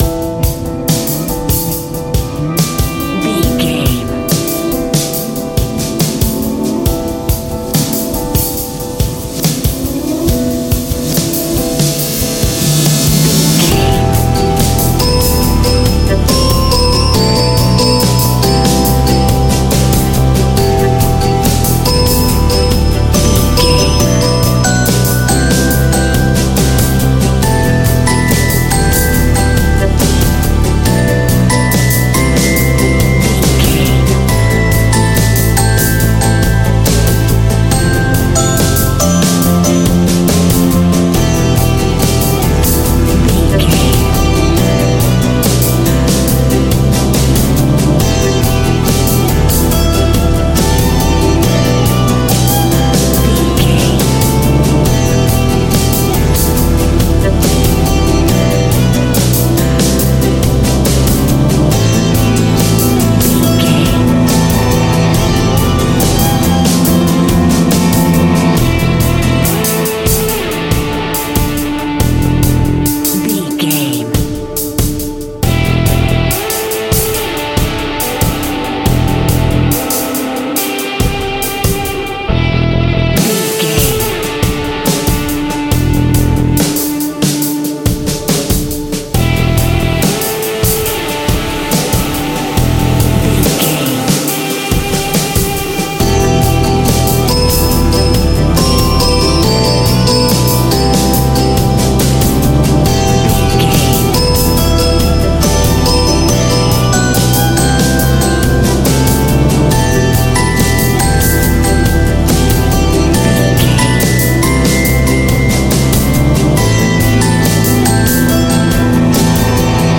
Aeolian/Minor
groovy
powerful
organ
drums
bass guitar
electric guitar
piano